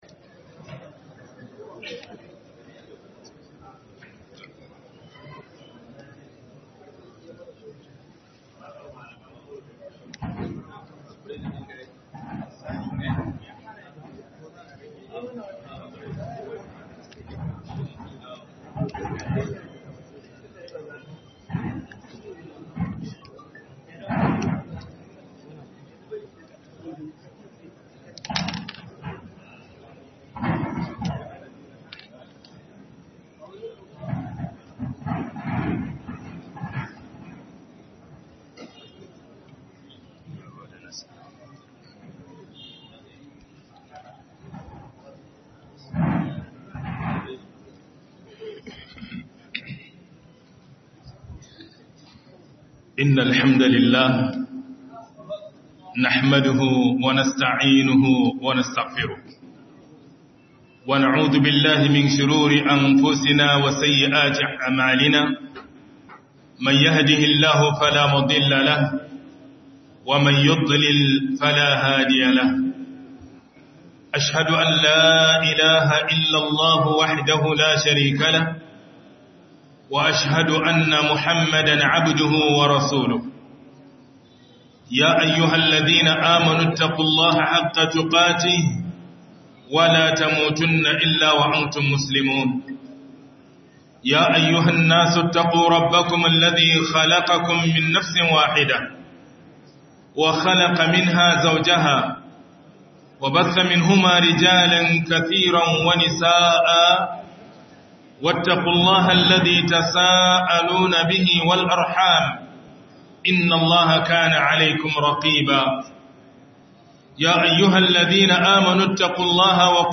SIFFAR MATASHI MUSULUMI NA KWARAI A MAHANGAR ADDININ MUSULUMCI DA AL'ADA - Muhadara